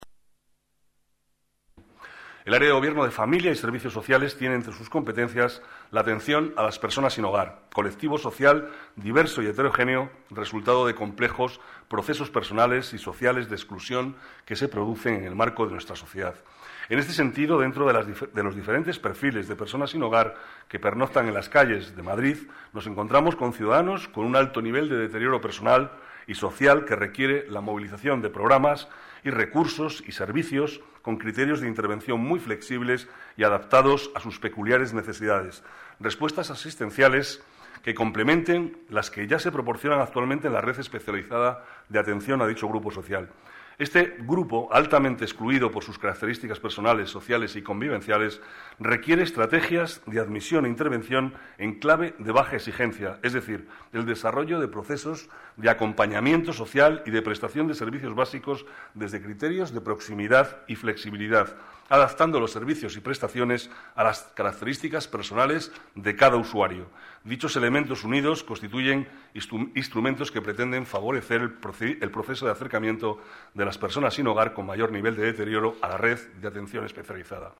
Nueva ventana:Declaraciones del vicealcalde, Manuel Cobo